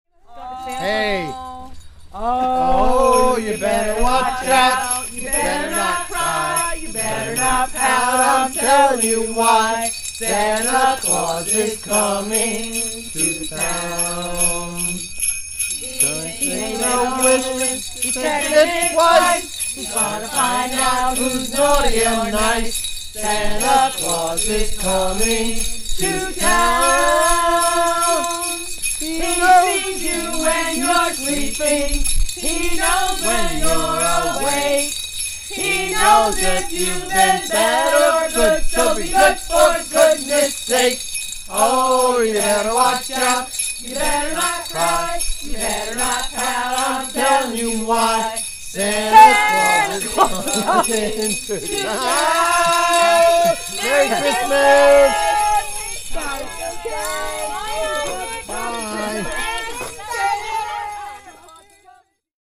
Athens carolers: Santa Claus is Coming to Town (Audio)